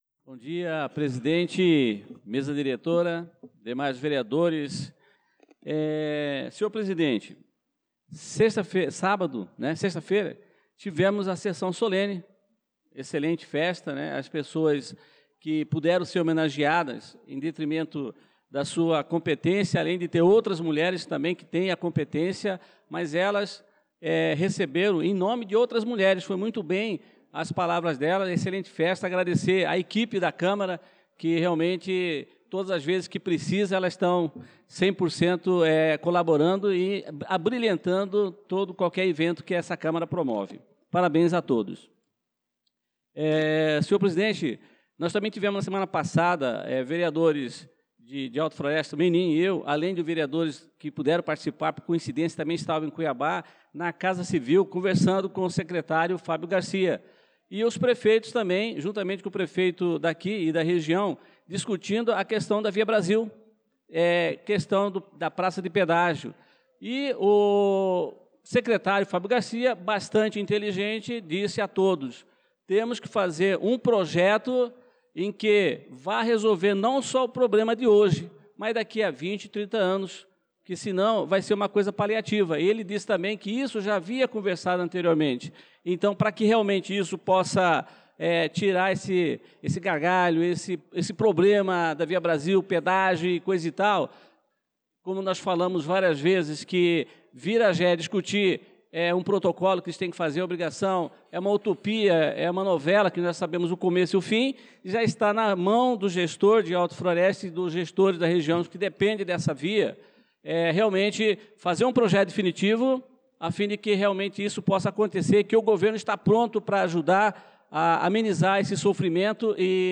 Pronunciamento do vereador Adelson Servidor na Sessão Ordinária do dia 01/04/2025